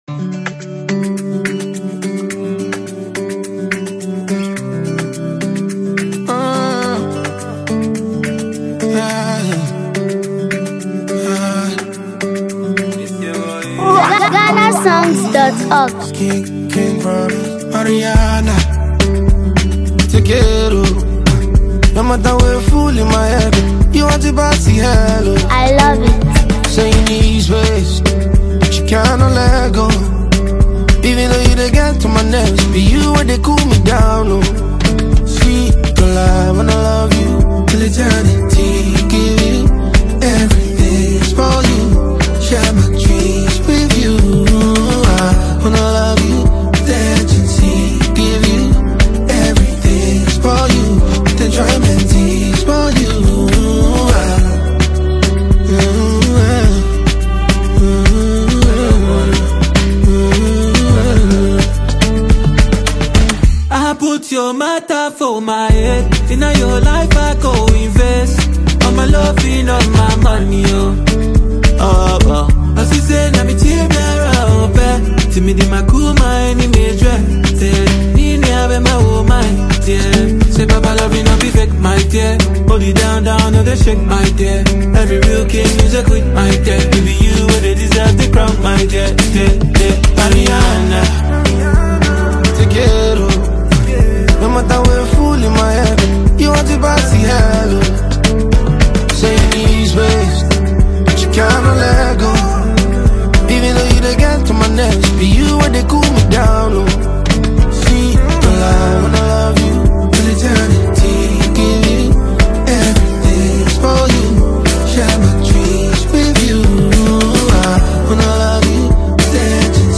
smooth and captivating track
smooth Afrobeat love songs